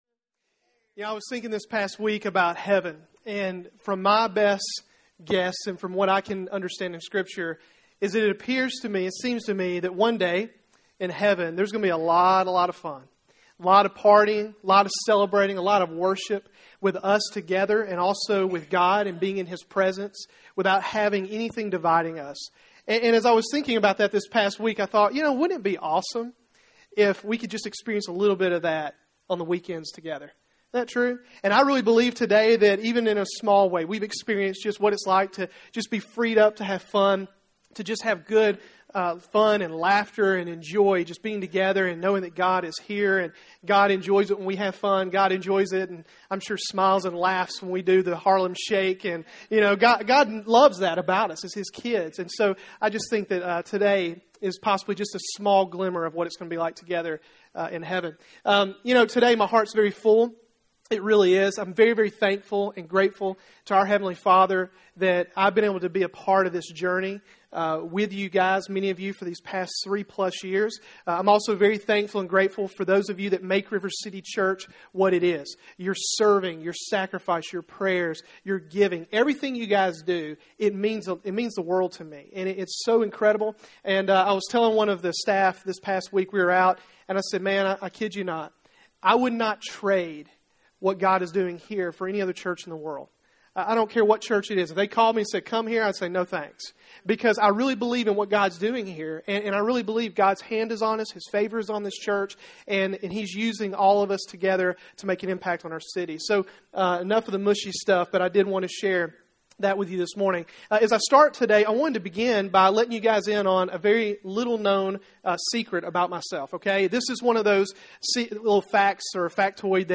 1. Reach-3 Year Birthday of River City Church